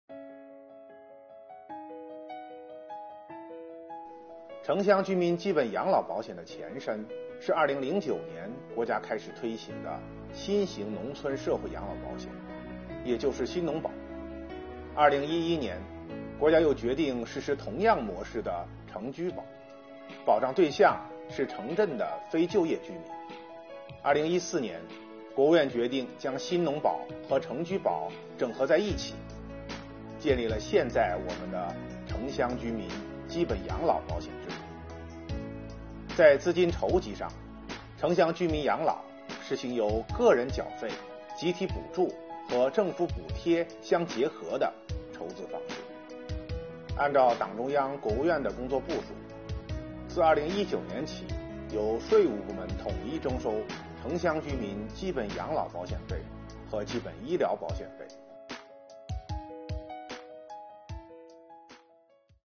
8月25日，国家税务总局推出“税务讲堂”网上公开课，由税务总局社会保险费司副司长杜志农详细解读城乡居民“两险”征缴服务，并集中解答缴费人普遍关心的问题。